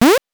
8 bits Elements
powerup_40.wav